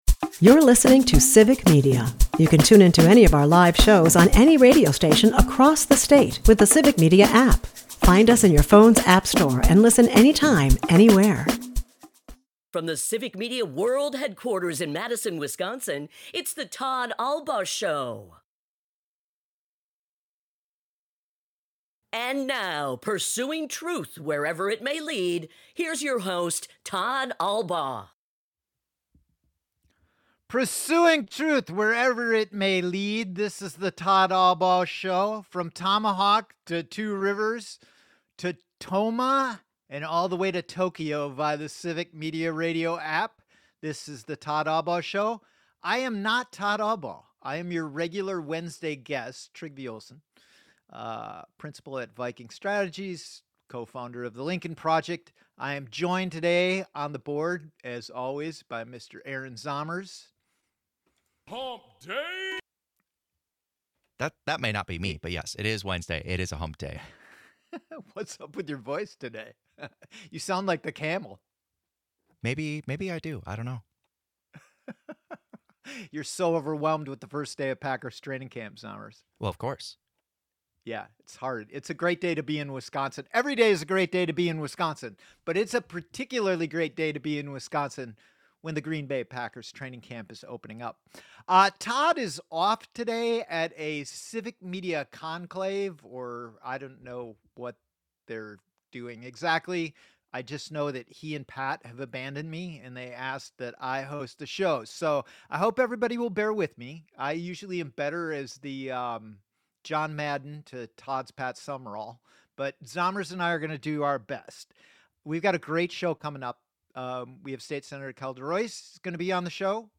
At the bottom of the hour, State Senator Kelda Roys returns to the program to discuss the impact of our new state budget on the UW System.